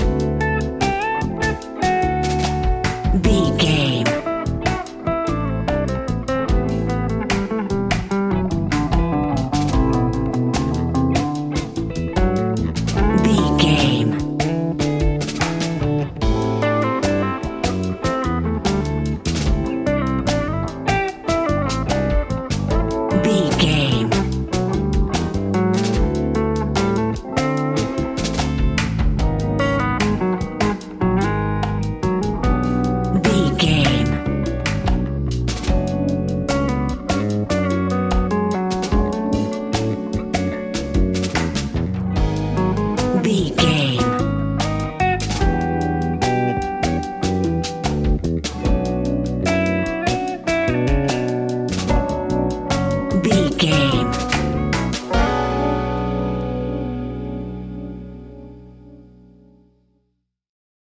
Ionian/Major
D♭
hip hop